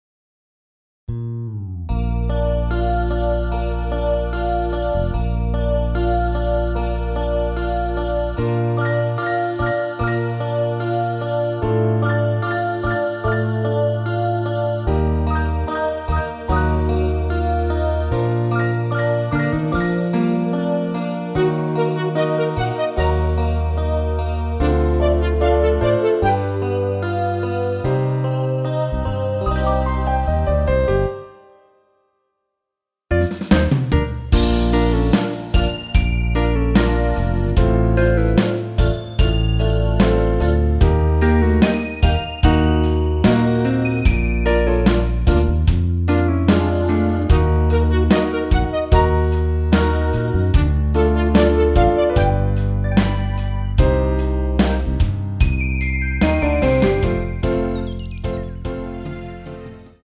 엔딩이 페이드 아웃이라 라이브하기 좋게 엔딩을 만들어 놓았습니다.
Bb
앞부분30초, 뒷부분30초씩 편집해서 올려 드리고 있습니다.
중간에 음이 끈어지고 다시 나오는 이유는